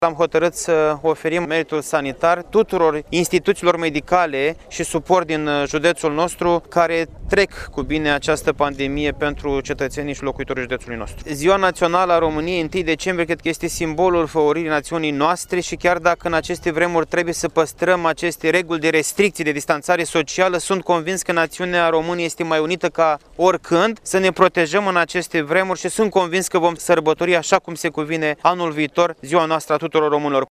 Manifestările din acest an prilejuite de Ziua Națională a României s-au desfășurat, la Iași, pe pietonalul Ștefan cel Mare în fața statuii Regelui Ferdinand.
Președintele Consiliului Județean, Costel Alexe:
1-dec-rdj-17-Alexe-ziua-nationala.mp3